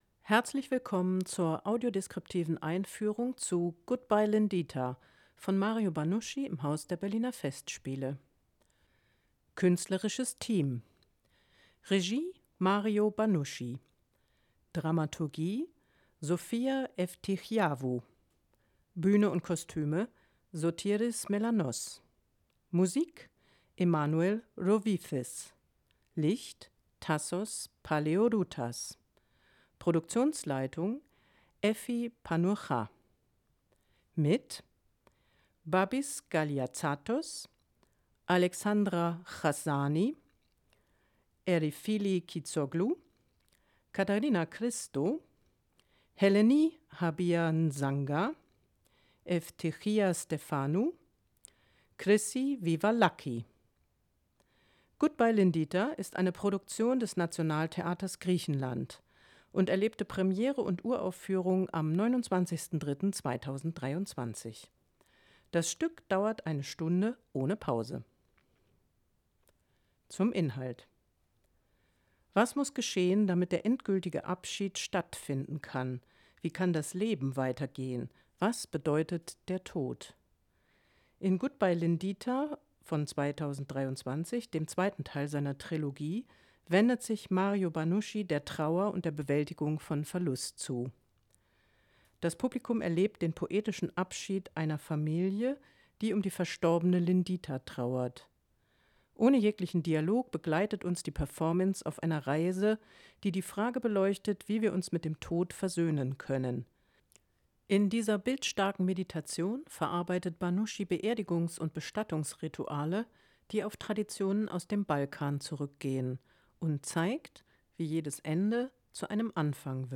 Audiodeskription „Goodbye, Lindita”
AudiodeskriptiveEinführung herunterladen